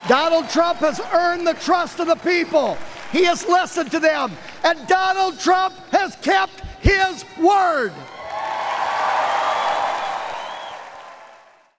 Iowa G-O-P chairman Jeff Kaufmann was chosen to kick off the process with a nominating speech.